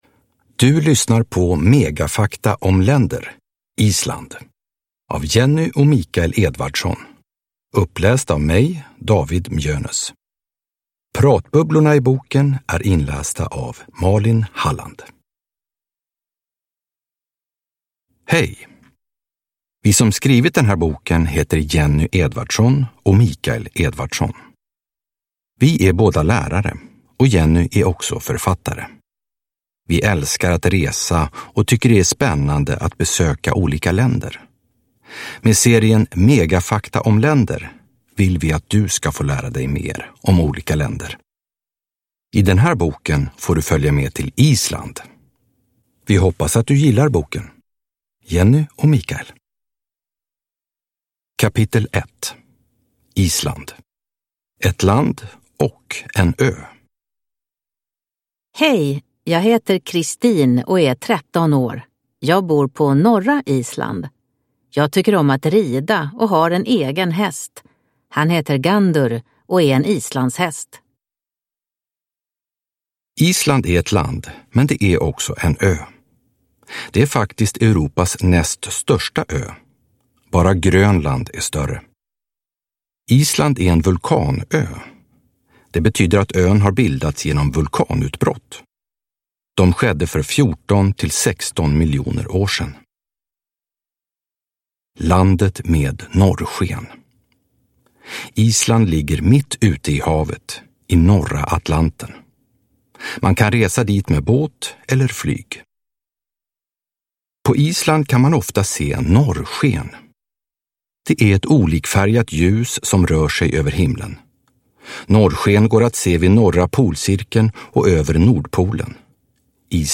Megafakta om länder. Island (ljudbok) av Jenny Edvardsson | Bokon